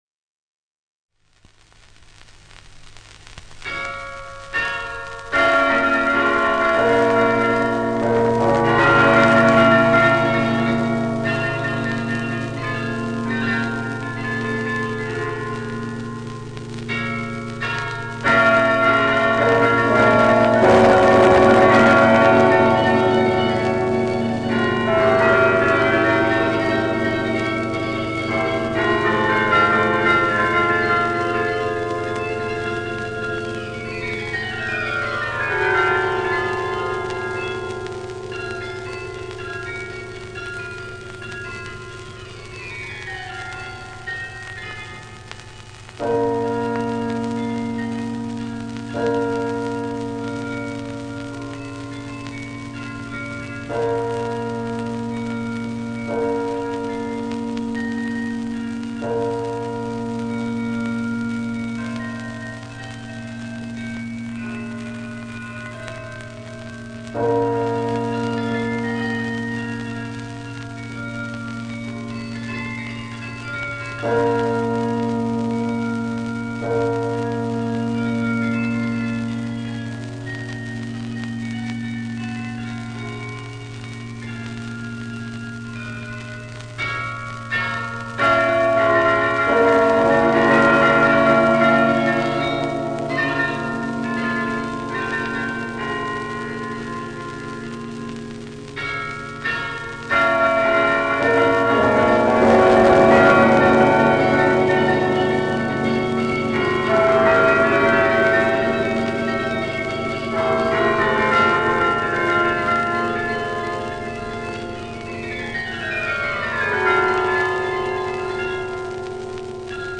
The Carillon